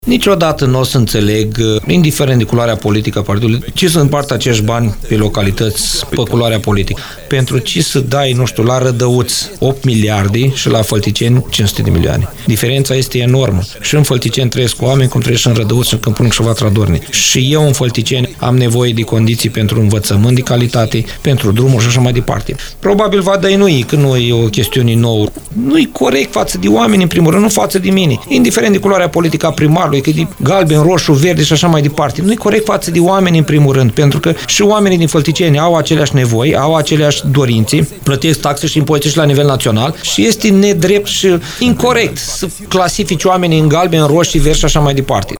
COMAN a declarat postului nostru de radio că primii care suferă în urma deciziilor politice nu sunt primarii localităților, ci chiar cetățenii acestora.